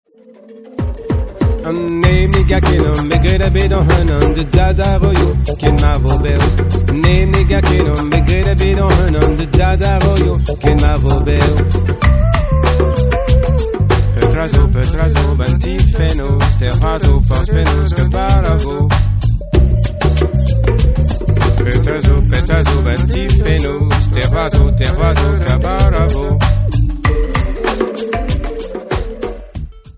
A breton musician - Un musicien breton - Ur sonner breizad
MP3 64kbps-Stereo